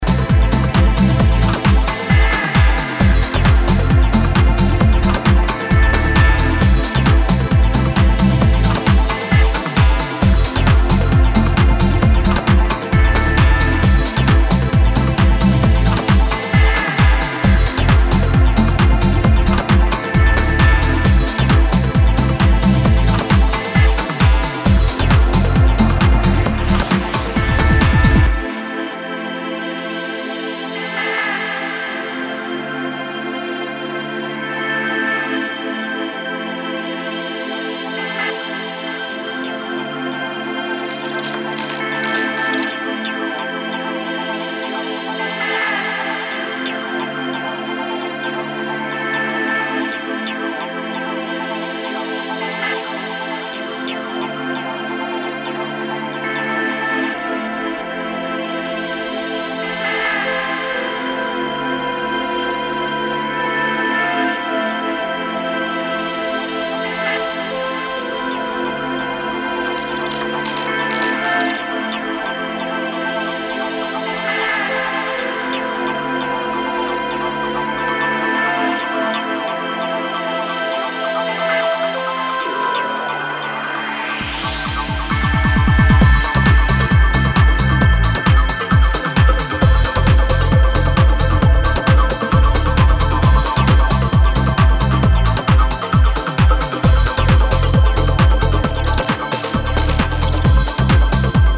a levitating remix